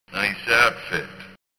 As an aside, it obviously doesn't come across at all in still pictures, but every level is peppered with voice clips direct from the film.
He applauds your dress sense by booming